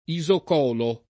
isocolo [ i @ ok 0 lo ]